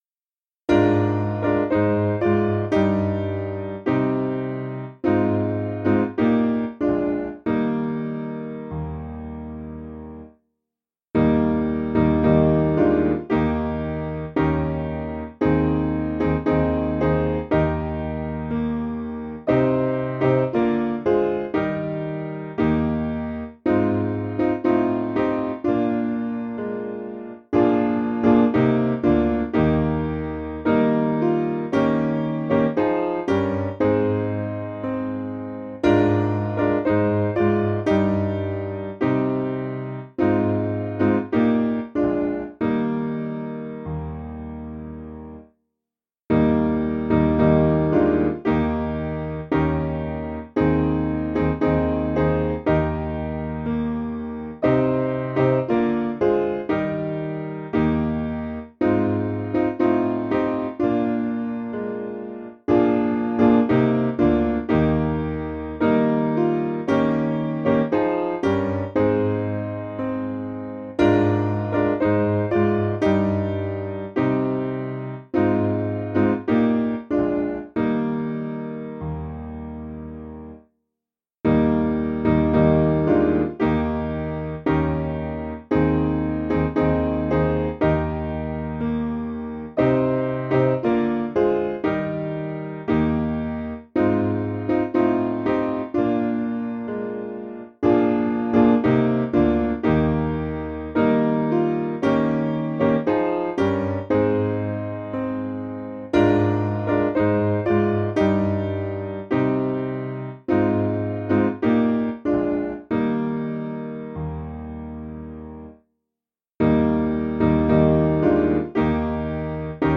Simple Piano
(CM)   4/Eb 477.2kb